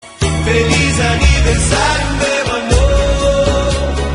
Trechinho do refrão da música de pagode romântico